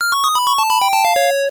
square pitch down
Category 🎮 Gaming
audio bruh ether ether-audio etheraudio retro square synth sound effect free sound royalty free Gaming